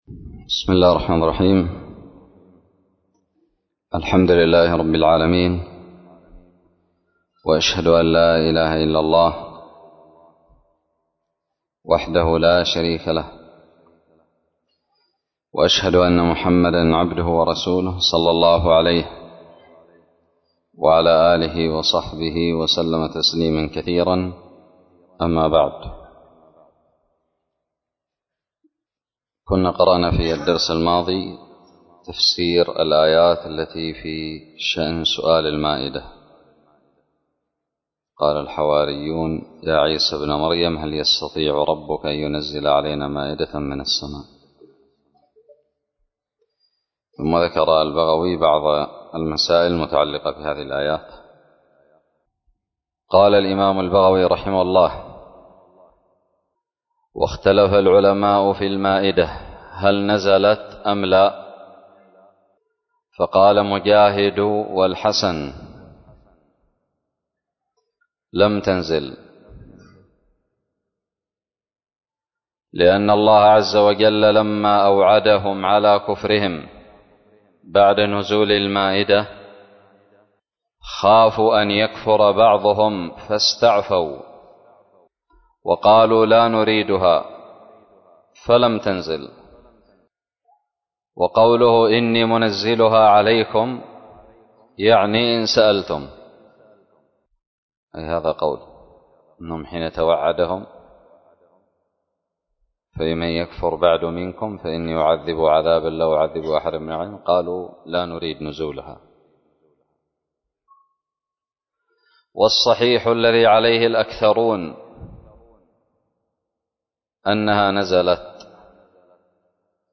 الدرس الخامس والستون من تفسير سورة المائدة من تفسير البغوي
ألقيت بدار الحديث السلفية للعلوم الشرعية بالضالع